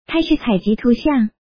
takephoto.mp3